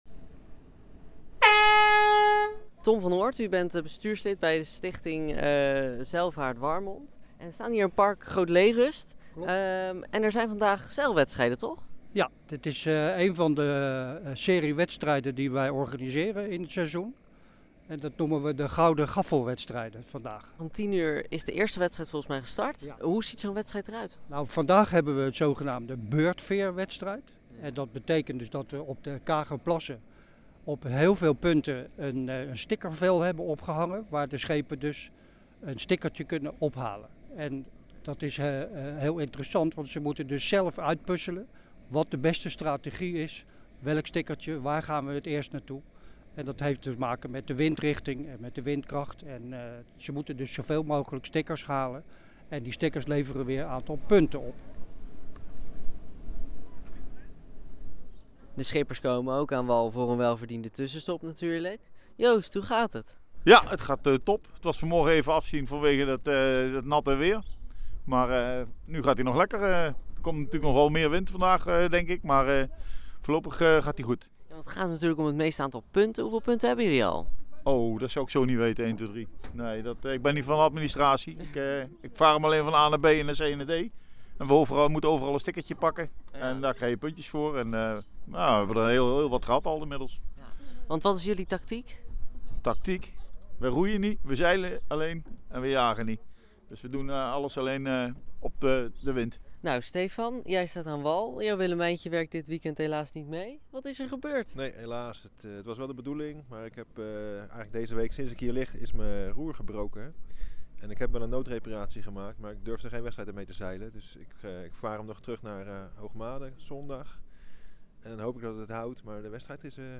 ging langs bij de zeilers in Park Groot Leerust en maakte er een reportage